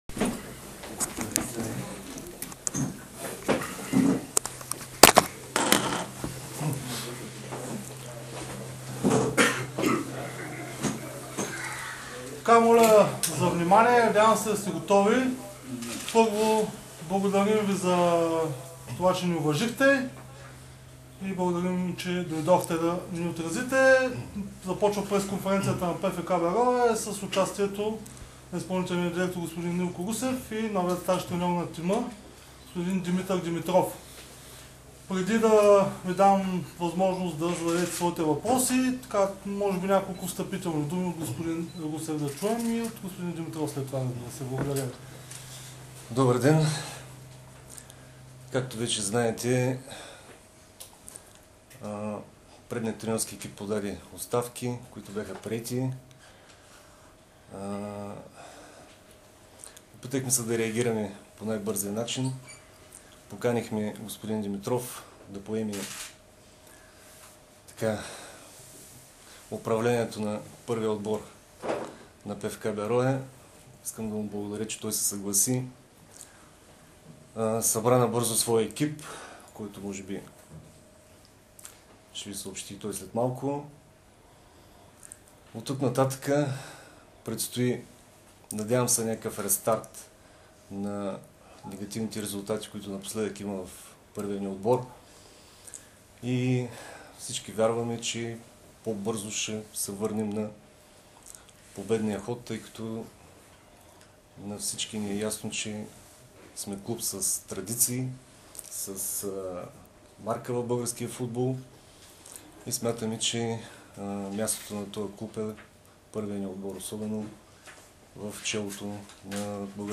Това заяви новият треньор на старозагорци Димитър Димитров по време на официалното си представяне. Херо заяви, че основната му цел е тимът да излезе от дупката, в която се намира и да опита да скъси разликата дистанцията с водещите тимове в оставащите 7 кръга до края на годината.